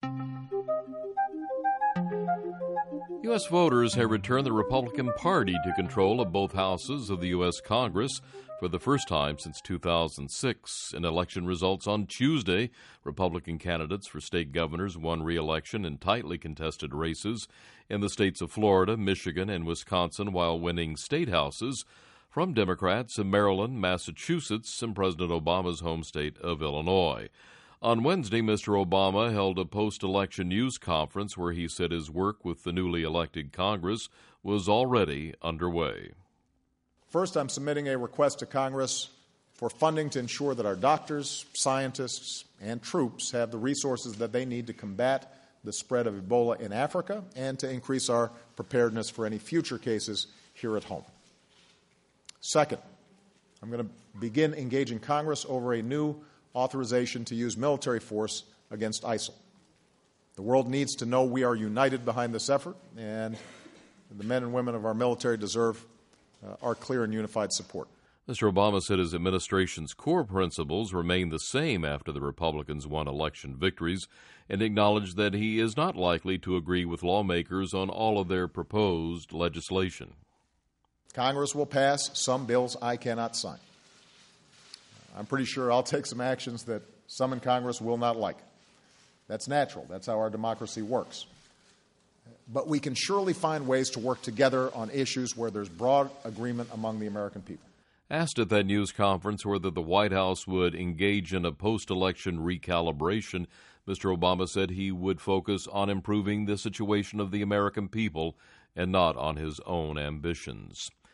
Post Election News Conference
US Voters Have Returned The Republican Party To Control Both Houses Of The US Congress For The First Time Since 2006 In Elections Results Tuesday. On Wednesday President Obama Held A Post-Election News Conference, Where He Said His Work With The Newly Elected Congress Was Already Underway.